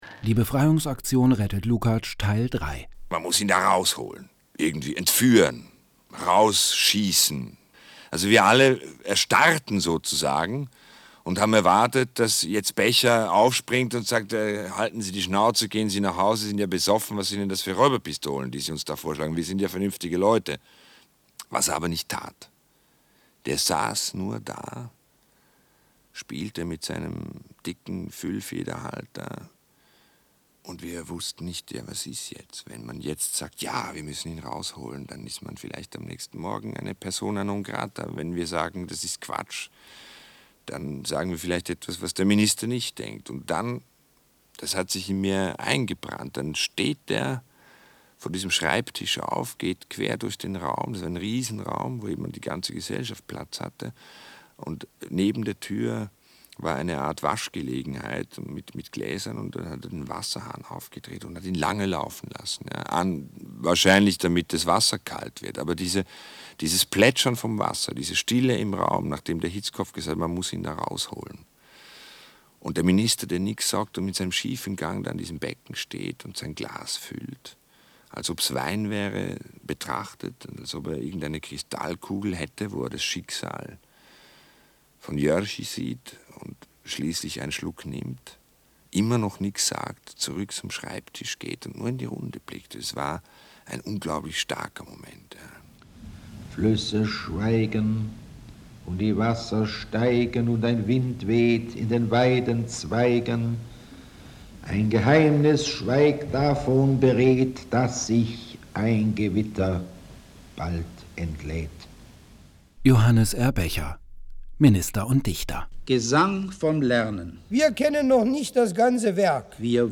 radiophone dokumentationen
dank der spielfreude des schauspielers dieter moor, der die rolle des chauffeurs besetzt, ist "mein mercedes steht zur verfügung" zu einem intensiven spiel von fiktion und realität geworden; zu einer auslotung der möglichkeiten des ERZÄHLENS im akustischen radiofeature.